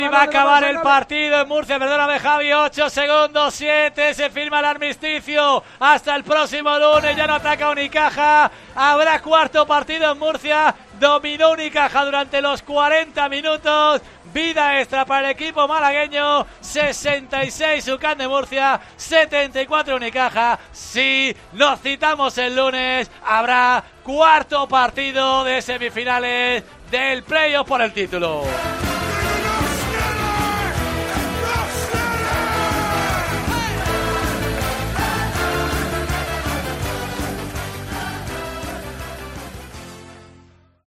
Así te narramos la victoria de Unicaja contra UCAM Murcia (66-74)